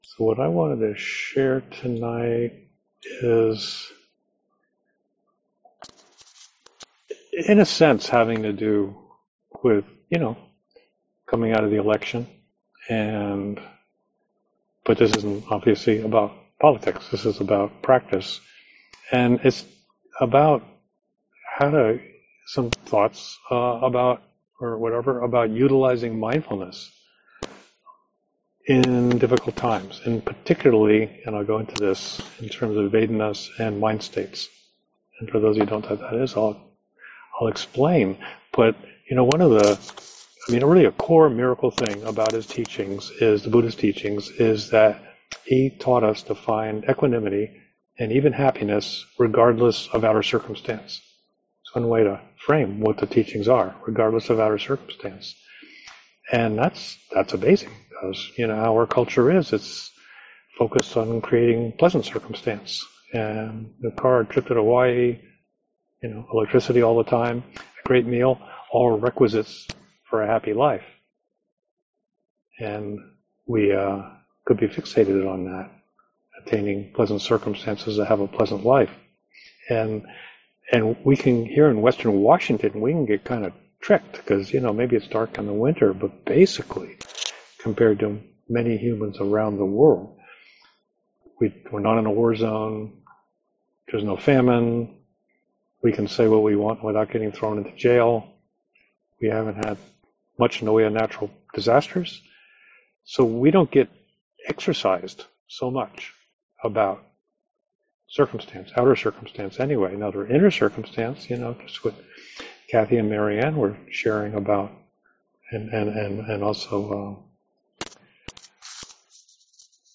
Audio includes clicks and other noise due to technical problems during recording.